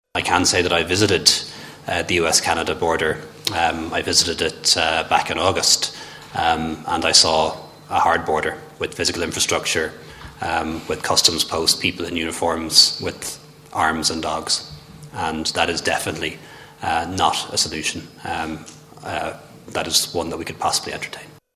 And Taoiseach Leo Varadkar also dismissed the suggestion: